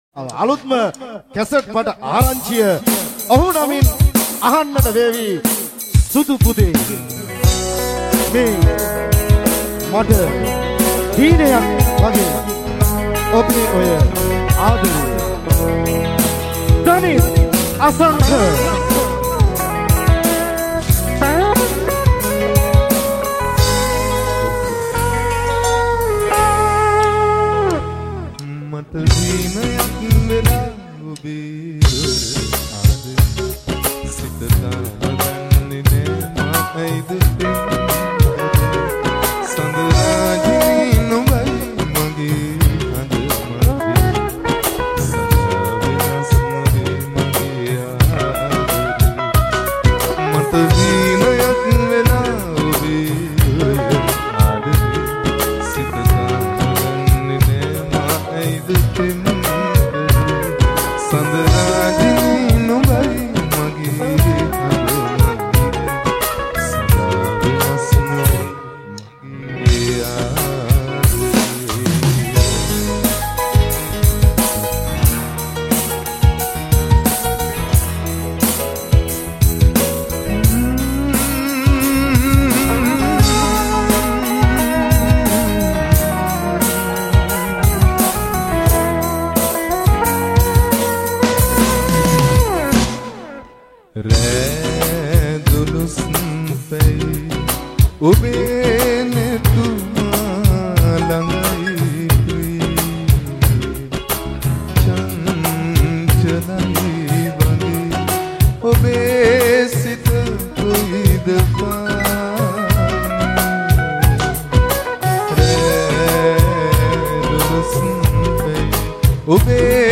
Category: Live Shows